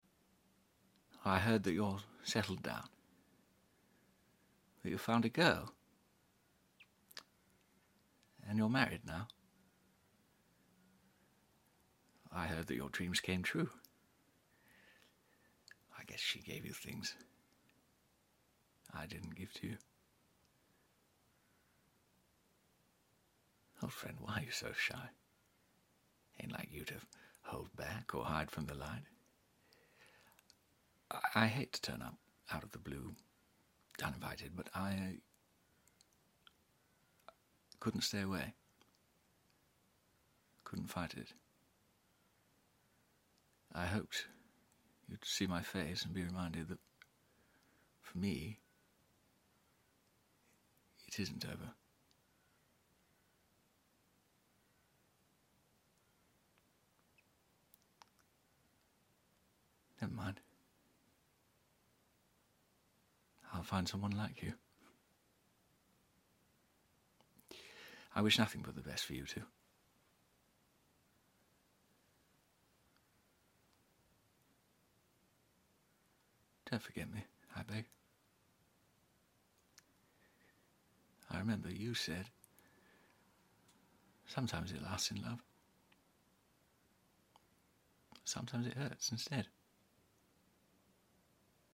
🎭 Dramatic Monologue: Someone Like